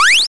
8-bit 8bit arcade blip bomb chip chiptune explosion sound effect free sound royalty free Gaming